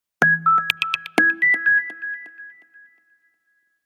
Index of /phonetones/unzipped/Nokia/6600-slide/Alert tones
Message 2.aac